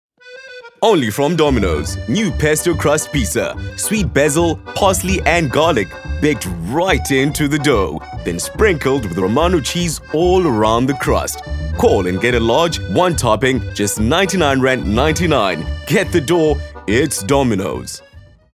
cultured, elegant, polished, refined
My demo reels